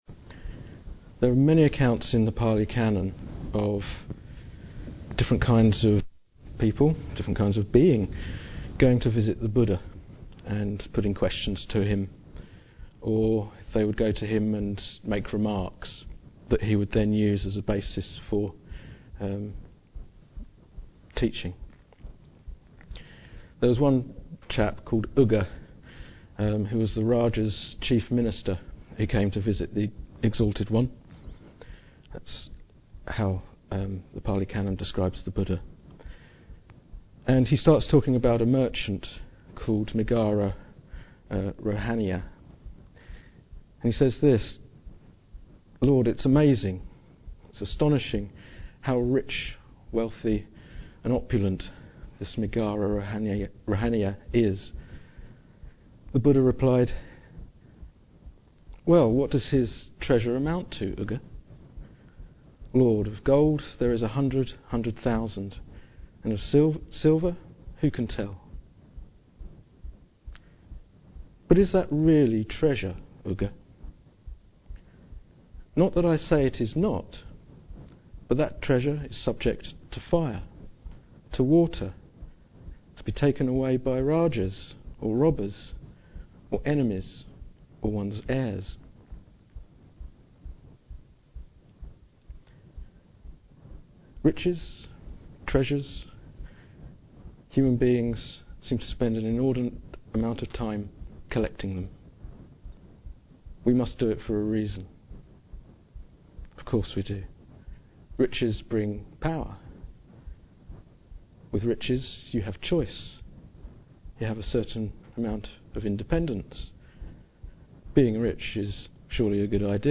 This talk was given in August 2009.